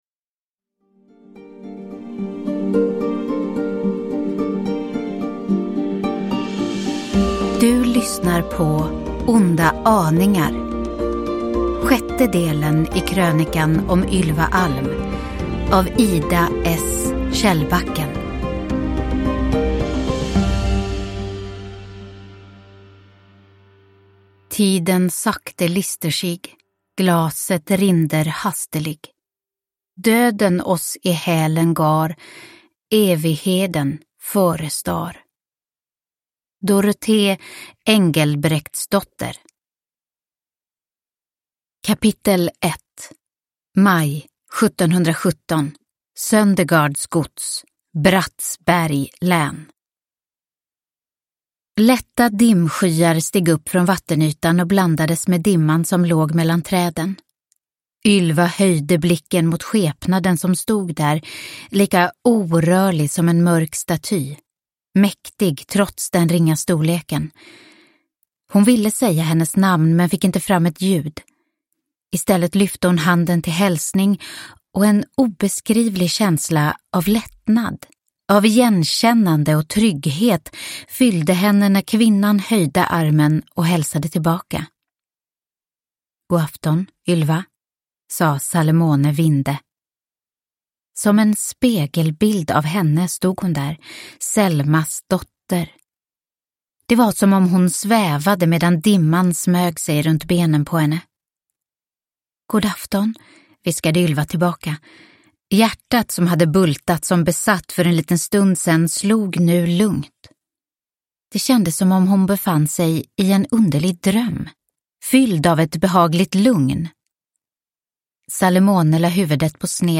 Onda aningar – Ljudbok – Laddas ner